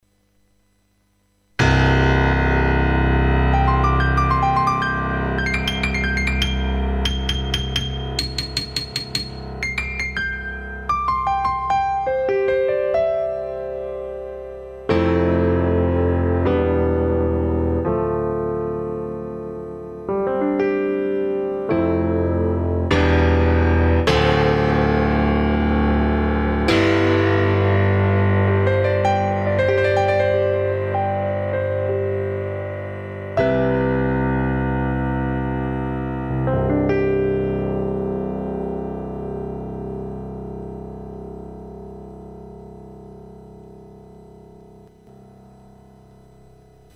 hq-test1 big pop piano preset843.mp3